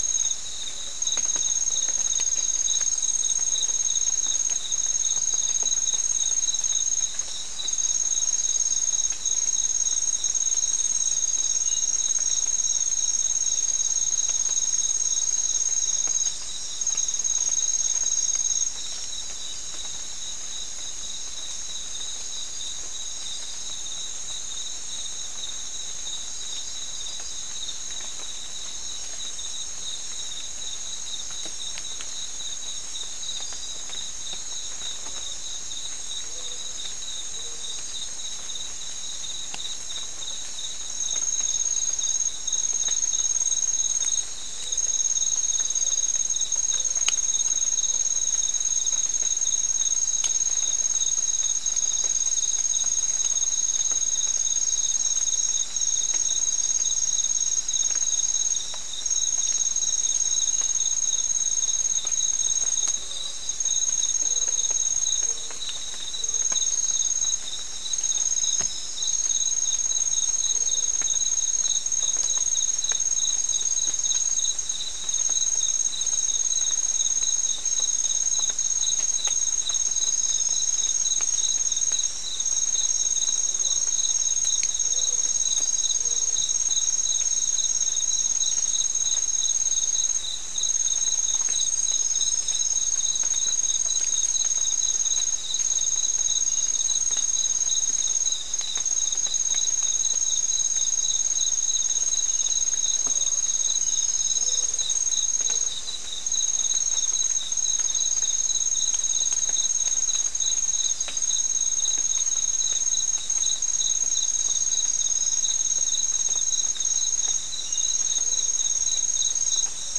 Soundscape
Location: South America: Guyana: Mill Site: 3
Recorder: SM3